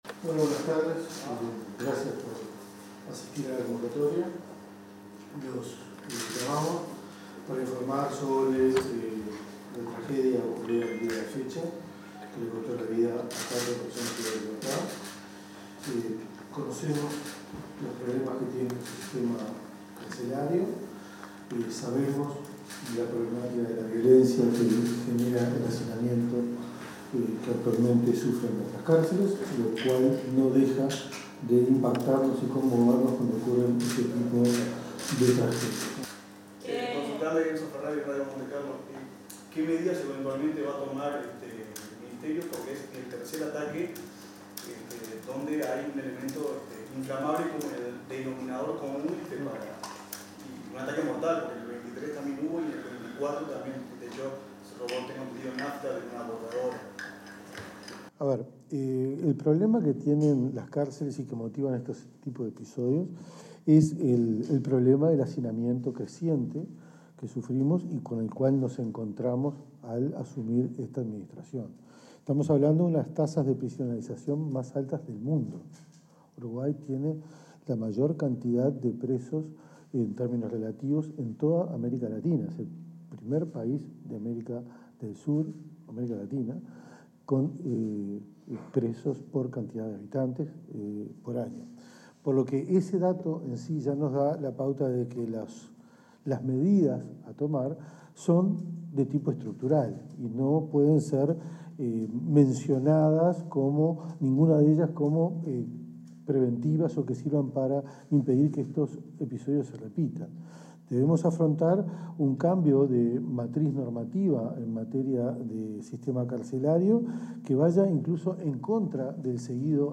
Declaraciones de autoridades del Ministerio del Interior
Declaraciones de autoridades del Ministerio del Interior 16/06/2025 Compartir Facebook X Copiar enlace WhatsApp LinkedIn Con motivo del incendio ocurrido en la Unidad n.° 4 de Santiago Vázquez, en conferencia de prensa el ministro del Interior, Carlos Negro; la directora del Instituto Nacional de Rehabilitación (INR), Ana Juanche, y el comisionado parlamentario, Juan Miguel Petit, respondieron las consultas de medios informativos.